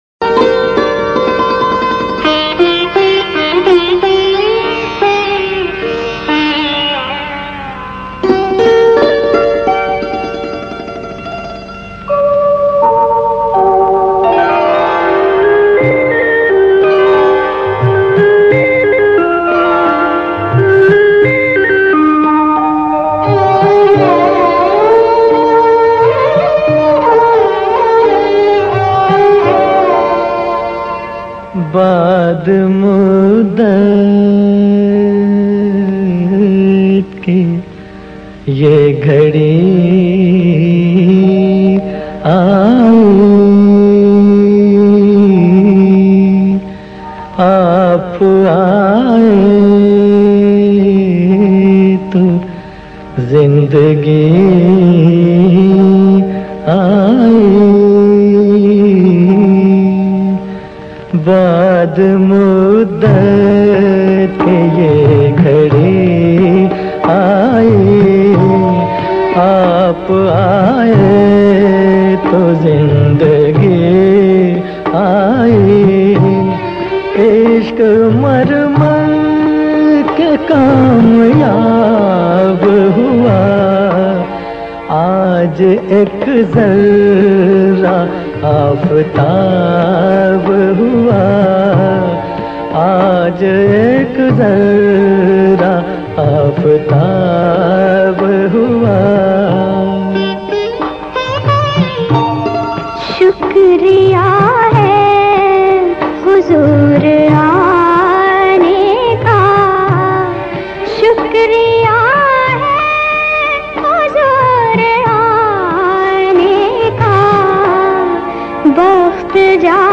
one of my favorite “Eid” related songs